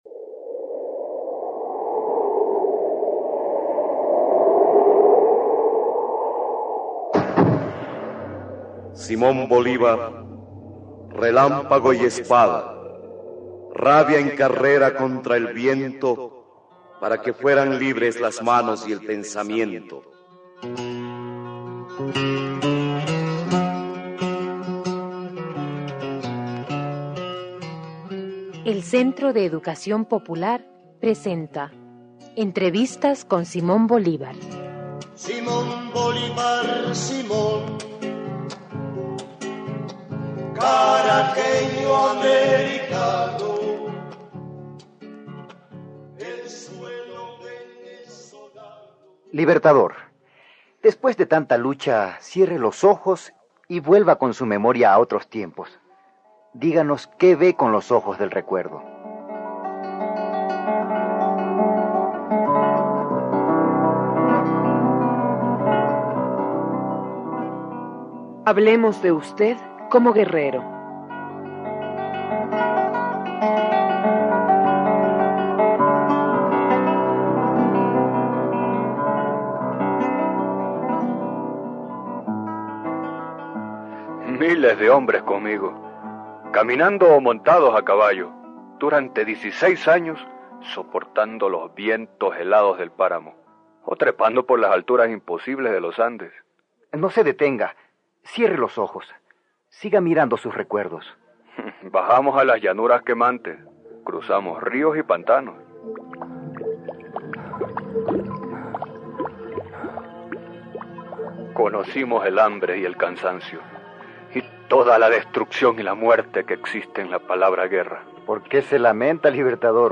RADIOTEATRO: Entrevistas con Simón Bolívar (capítulo 7) – Central de Trabajadores y Trabajadoras de la Argentina